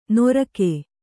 ♪ norake